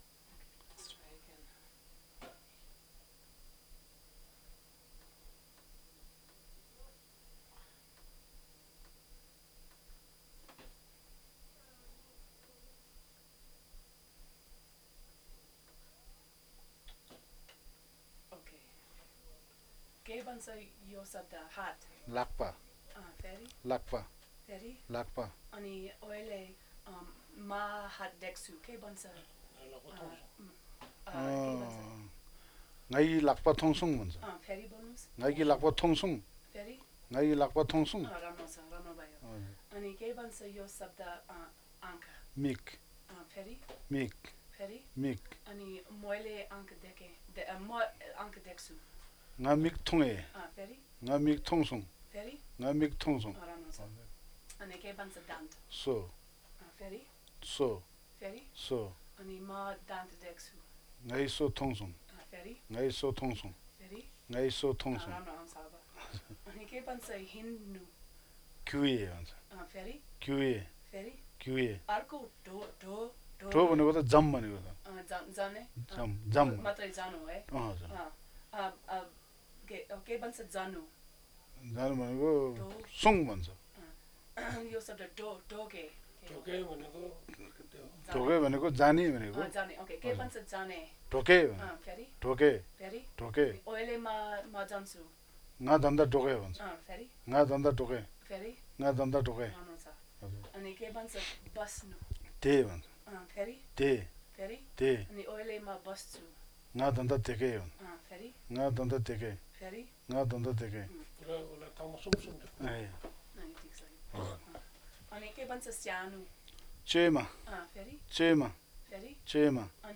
Word elicitations for acoustic and electroglottographic analysis of Gyalsumdo
Chame, Manang, Nepal